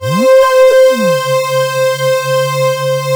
OSCAR 10 C5.wav